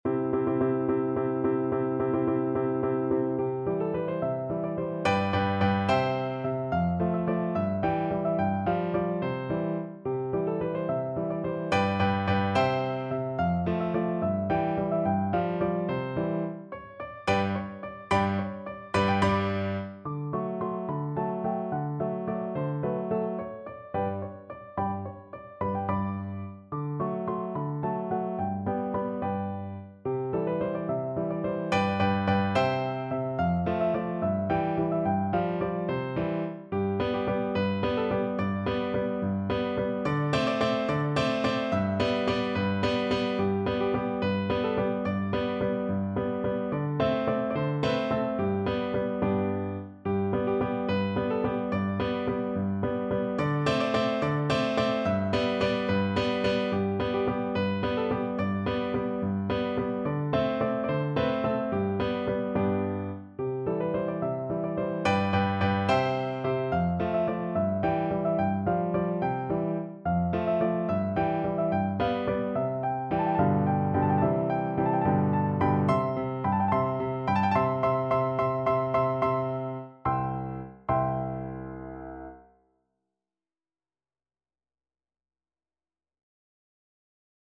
Traditional Trad. Jubelwalzer Piano version
No parts available for this pieces as it is for solo piano.
C major (Sounding Pitch) (View more C major Music for Piano )
3/8 (View more 3/8 Music)
Piano  (View more Intermediate Piano Music)
Traditional (View more Traditional Piano Music)